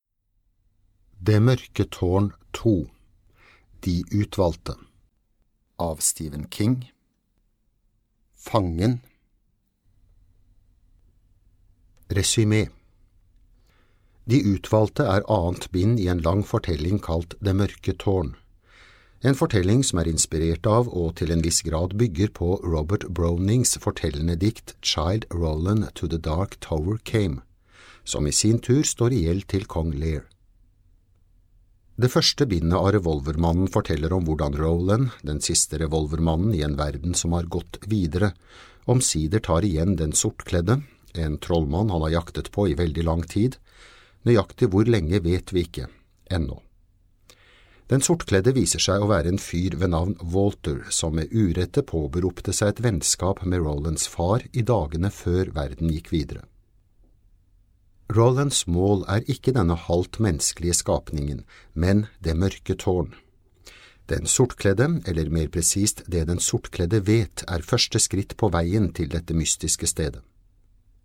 Det mørke tårn II - Del 1 - Fangen (lydbok) av Stephen King